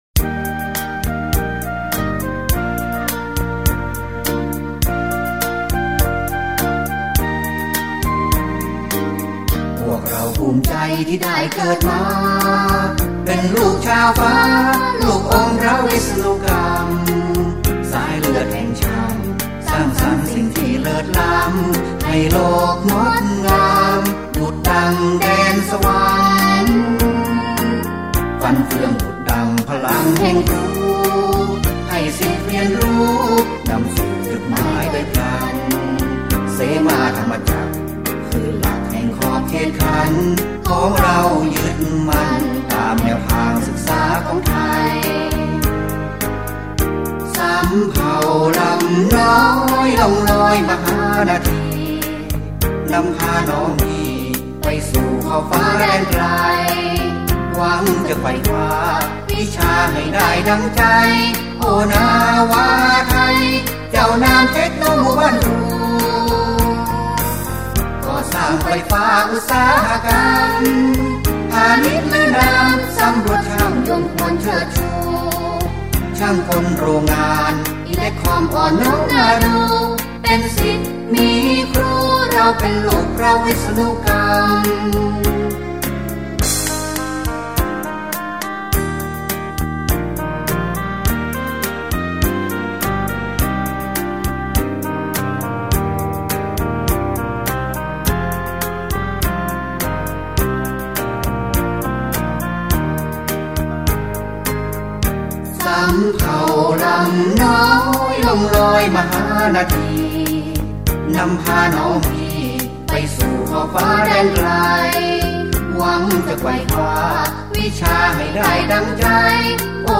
เพลงมาร์ชวิทยาลัย